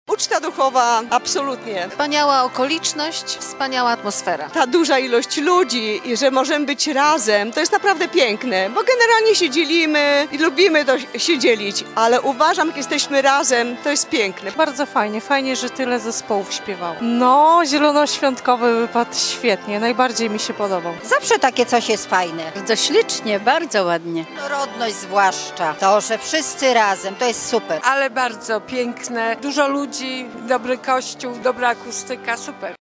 Wspólne kolędowanie wyznawców różnych kościołów to doskonały sposób na integrację mieszkańców – mówili reporterowi Radia 5 uczestnicy spotkania.